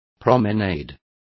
Complete with pronunciation of the translation of promenade.